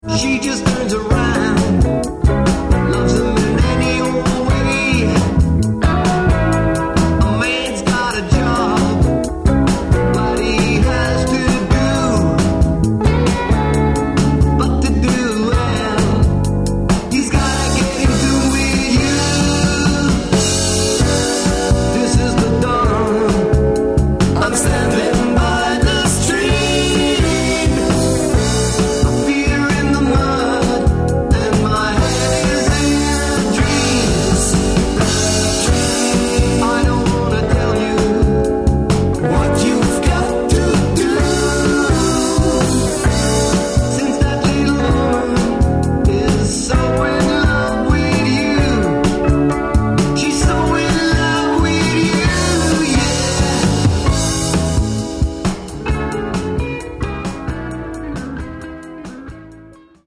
Four brothers and a cousin who play music.